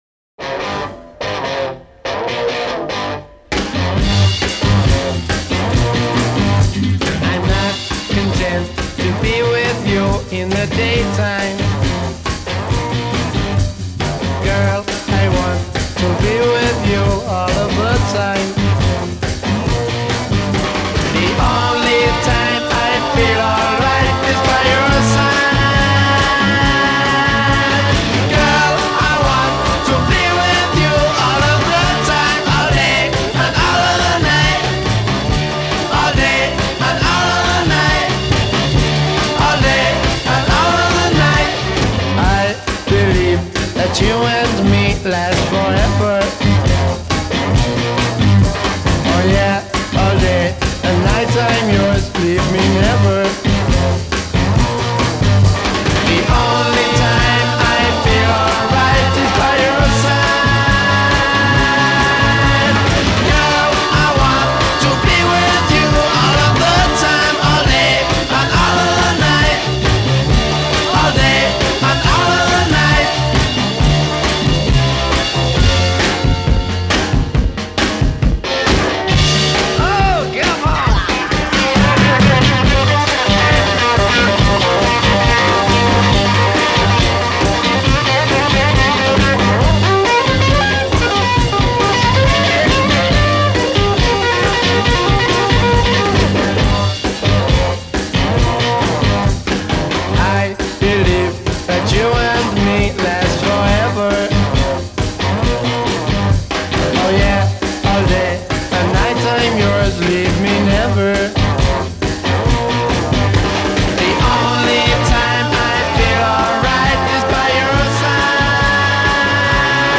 lead vocal and guitar
bass
maracas
drums
Intro 0:00 8 guitar chords, add ensemble
refrain : 24 as above, add sustaining vocal harmonies b
A* verse : 16 + 4 guitar solo over chord riff in tonic
outro : 4 guitar chords with bass and drums in unison